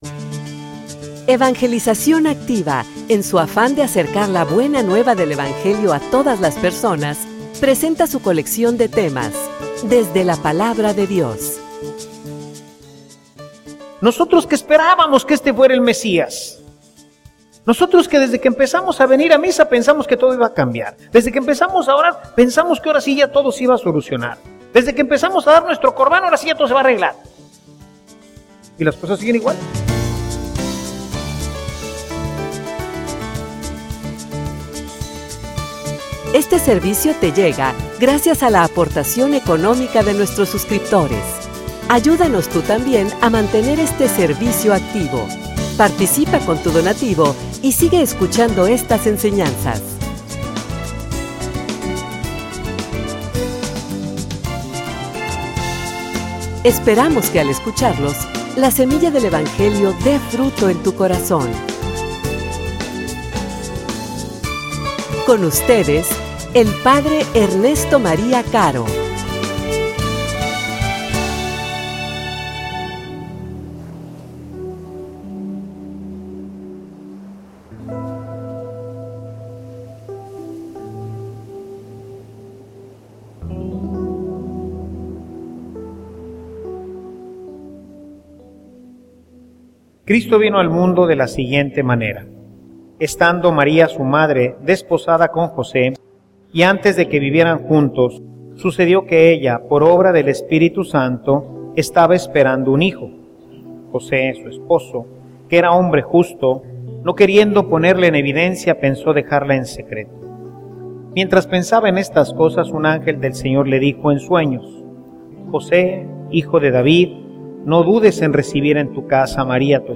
homilia_La_Palabra_fuente_de_esperanza.mp3